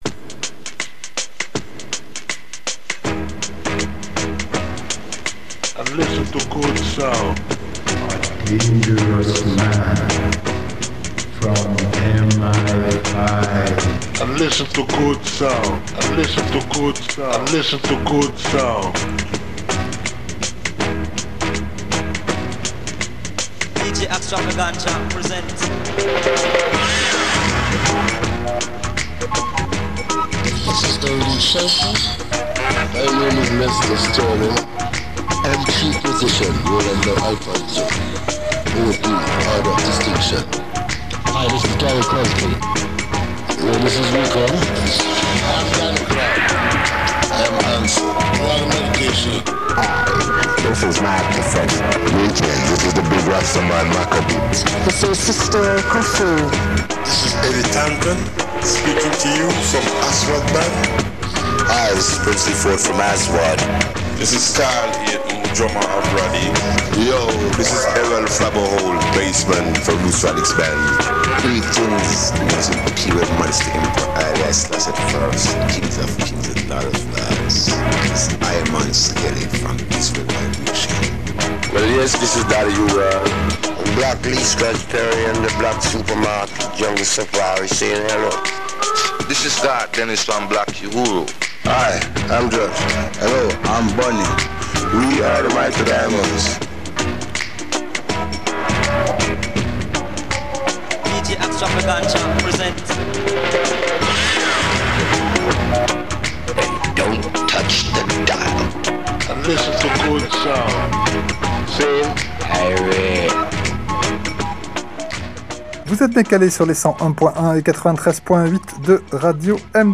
Black Super Market – radio show !
ska, afrobeat, dub, salsa, funk, mestizo, kompa, rumba, reggae, soul, cumbia, ragga, soca, merengue, Brésil, champeta, Balkans, latino rock…